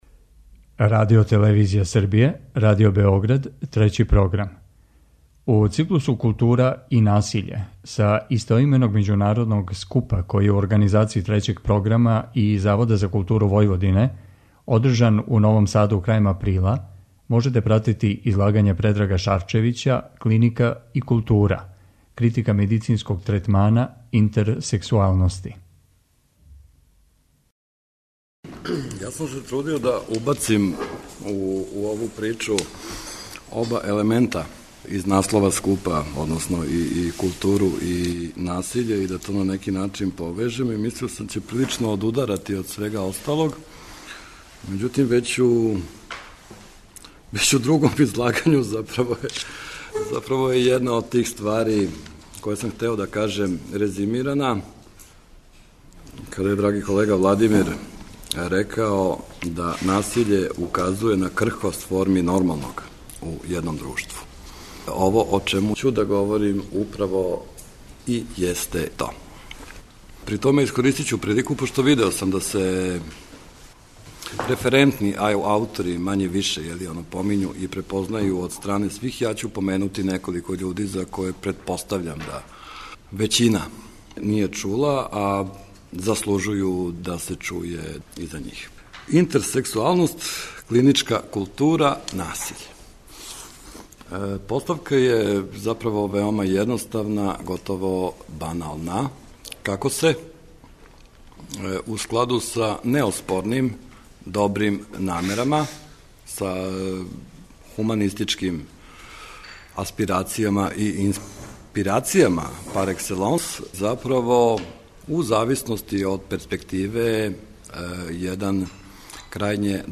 У циклусу КУЛТУРА И НАСИЉЕ, који емитујемо средом, са истоименог научног скупа који су, у Новом Саду крајем априла, организовали Трећи програм и Завод за културу Војводине, емитујемо прилоге са овога скупа и разговоре о излагањима.